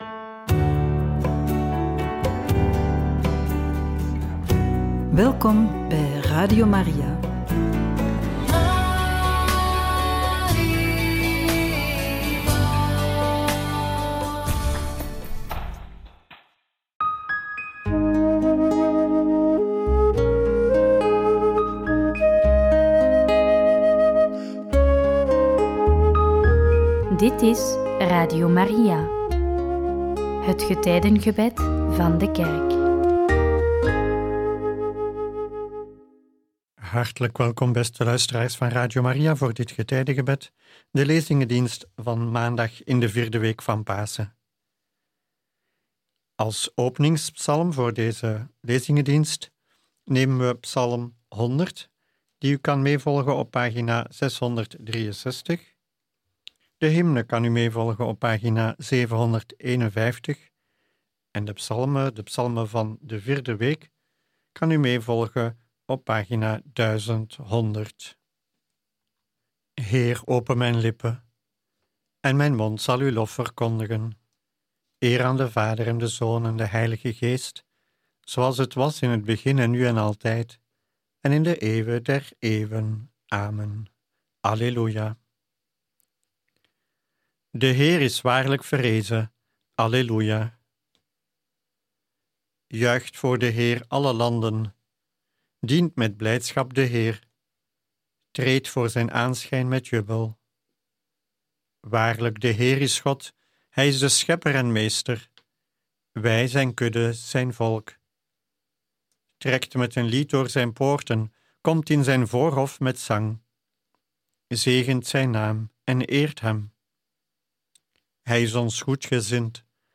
Lezingendienst